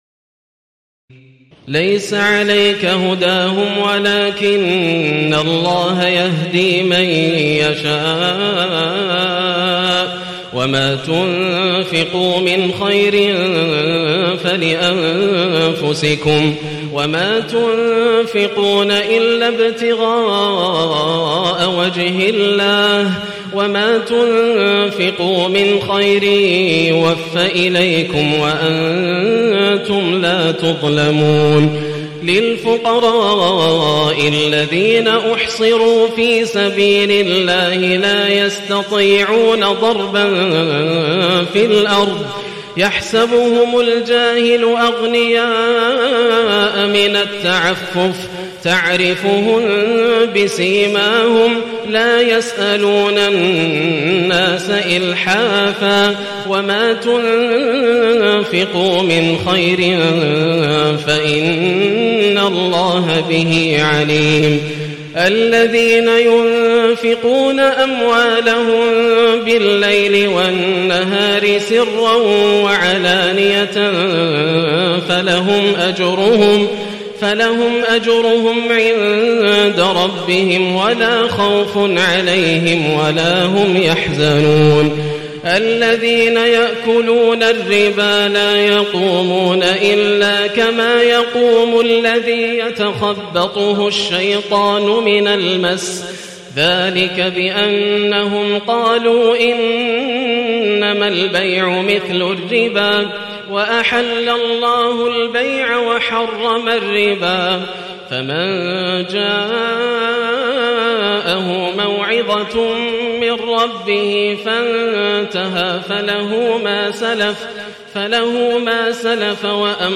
تراويح الليلة الثالثة رمضان 1437هـ من سورتي البقرة (272-286) و آل عمران (1-63) Taraweeh 3st night Ramadan 1437H from Surah Al-Baqara and Surah Aal-i-Imraan > تراويح الحرم المكي عام 1437 🕋 > التراويح - تلاوات الحرمين